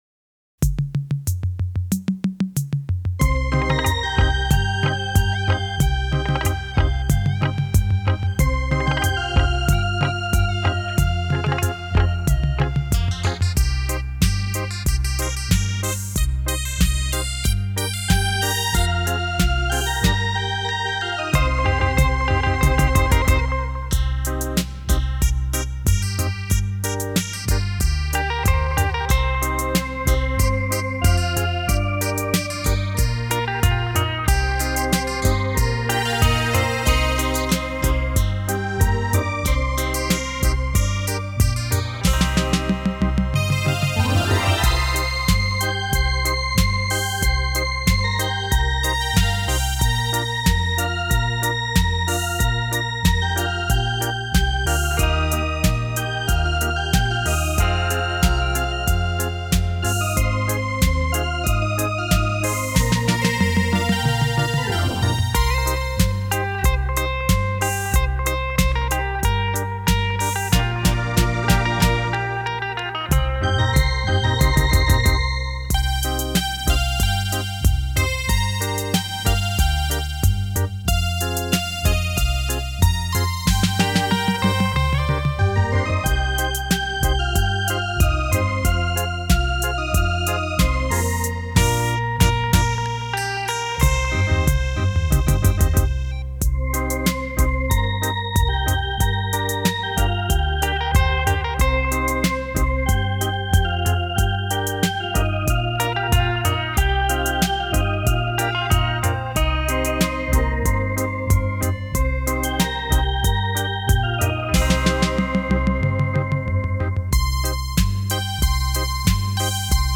音质相当好，谢谢楼主分享！
电子琴演奏的老歌很优美谢谢楼主！